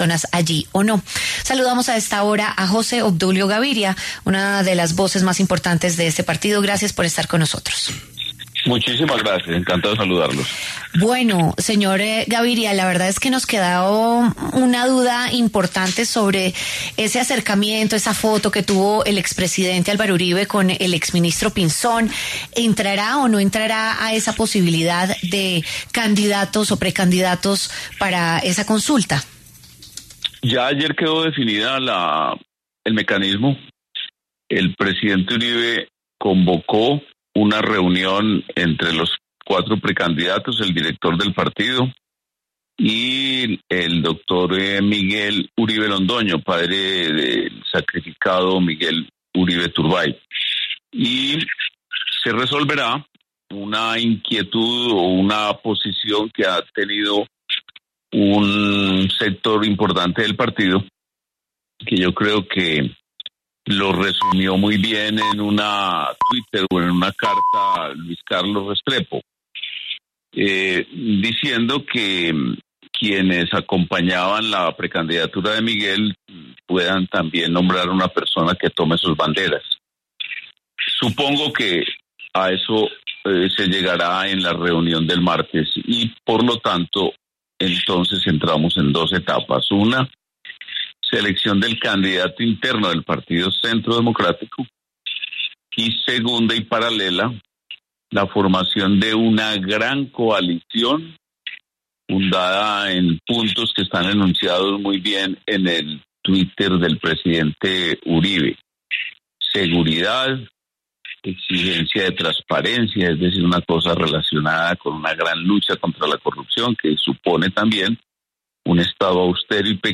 El exsenador José Obdulio Gaviria se refirió en La W a la posibilidad del ingreso de un nuevo nombre en la consulta de precandidatos del Centro Democrático de cara a las elecciones 2026.
Para hablar sobre el tema, pasó por los micrófonos de La W una de las voces más destacadas del partido, el exsenador José Obdulio Gaviria.